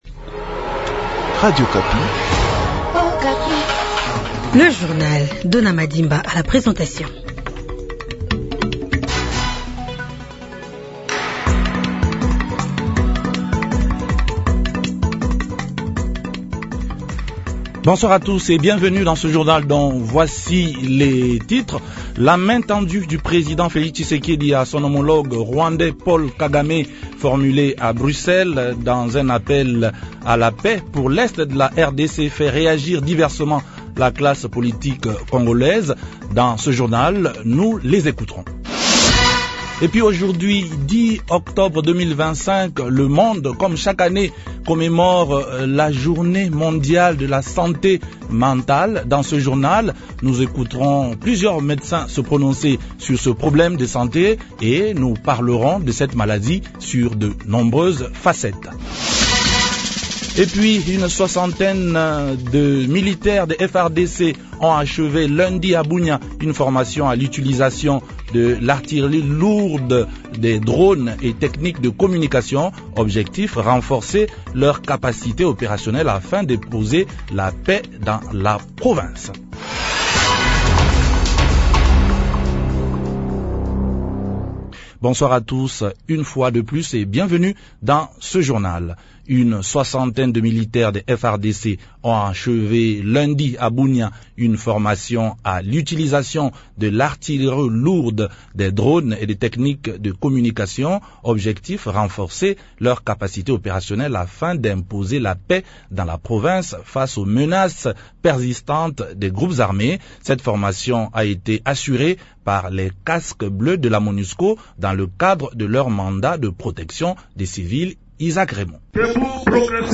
journal francais
La journée mondiale de la santé mentale c’est aujourd’hui 10 octobre. Dans ce journal nous évoquerons les différentes facettes de cette problématique de sante et a l’occasion nous écouterons aussi l’avis de nombreux médecins sur cette maladie.